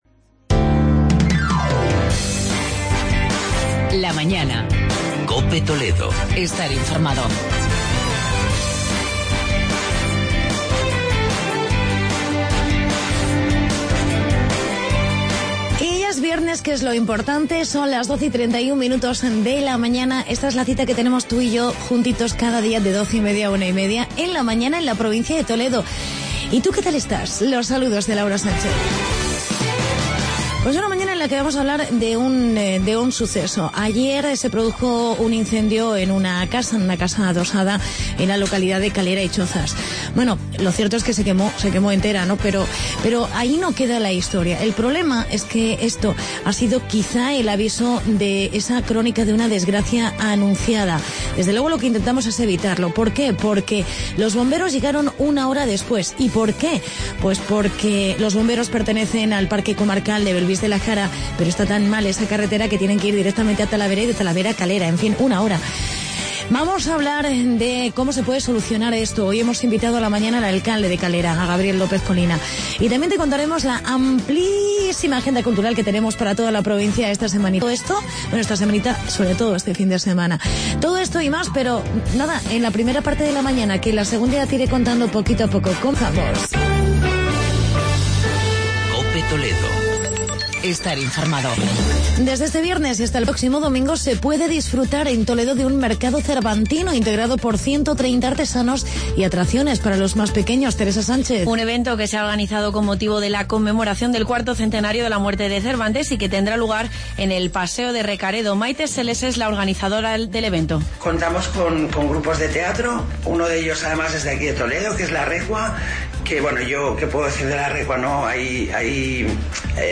Entrevista con el alcalde de Calera y Chozas, Gabriel López-Colina por el incendio ocurrido y el problema con los bomberos y Agenda Cultural.